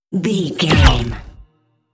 Sci fi gun shot whoosh fast
Sound Effects
Fast
futuristic
whoosh